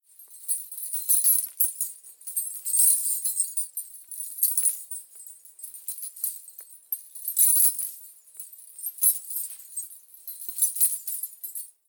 Danza árabe, bailarina haciendo el movimiento de camello 03
Sonidos: Música
Sonidos: Acciones humanas